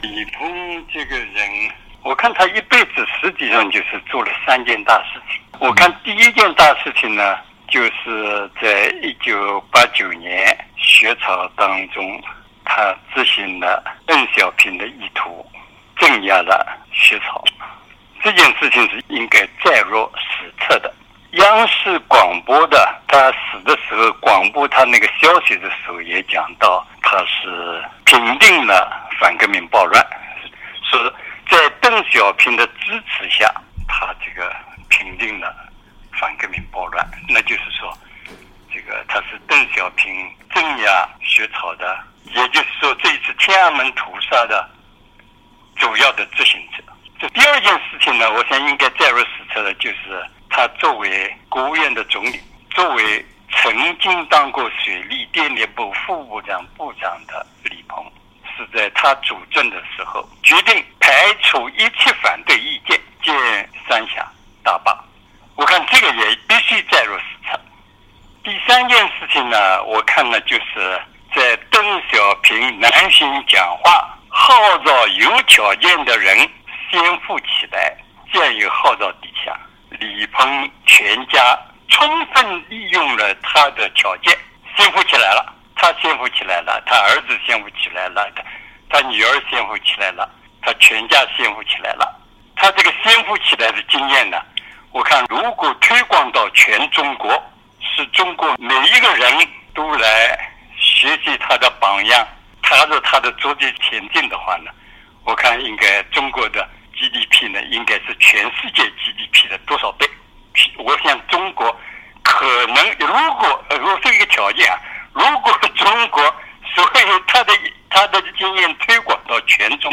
原中共中央委员、赵紫阳政治秘书鲍彤认为，原中国总理李鹏做了可以载入史册的三件大事：一是在邓小平指示下执行了六四镇压，二是主持修建了备受争议的三峡大坝，三是在邓小平让一部分人先富起来的精神指导下，全家人先富起来了。下面是鲍彤新近接受美国之音专访的第一部分（受访者观点不代表美国之音）。
（根据采访录音整理，受访者观点不代表美国之音）